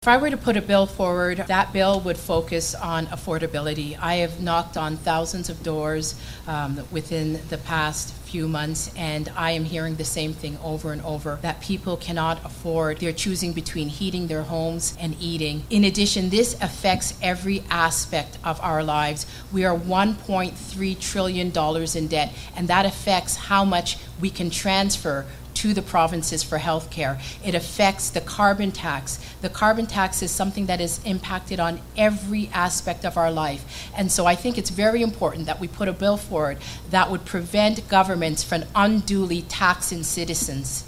All-candidates debate, hosted by the Simcoe and District Chamber of Commerce